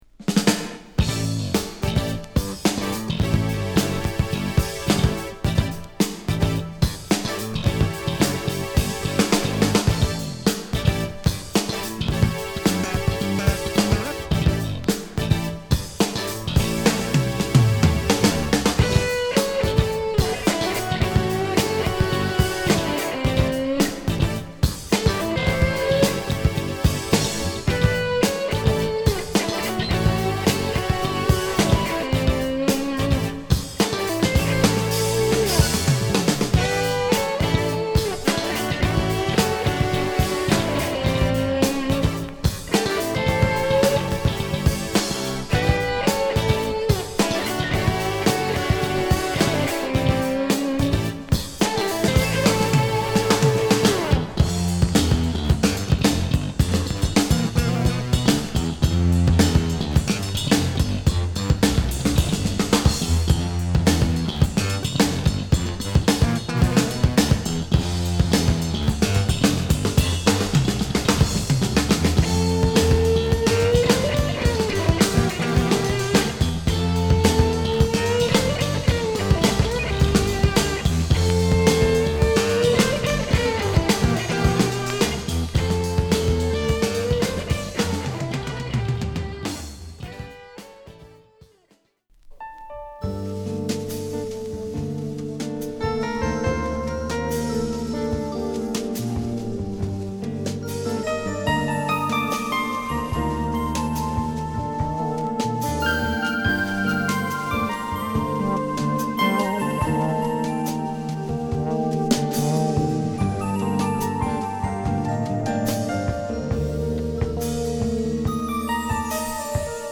独特の緩急ある演奏を聴かせてくれるマイナー・フュージョン・アルバム。